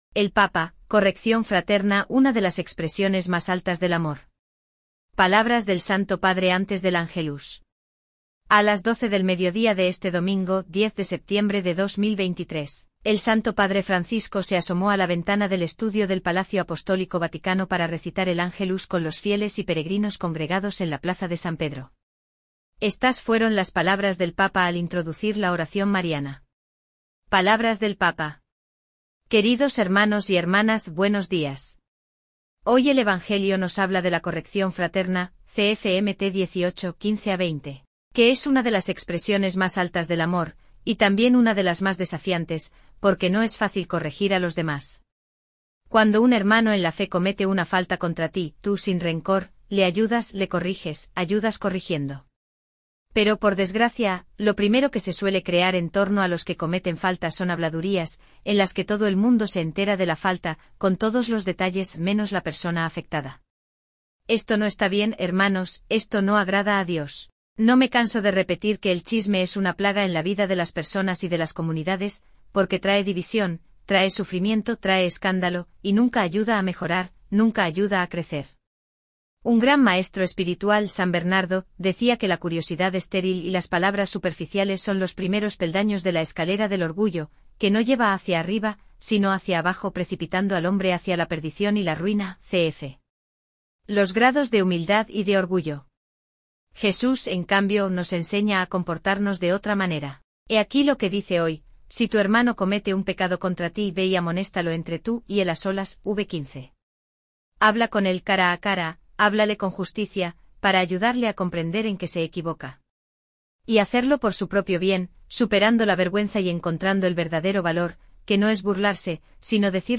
Palabras del Santo Padre antes del Ángelus
A las 12 del mediodía de este domingo, 10 de septiembre de 2023,  el Santo Padre Francisco se asomó a la ventana del estudio del Palacio Apostólico Vaticano para recitar el Ángelus con los fieles y peregrinos congregados en la Plaza de San Pedro.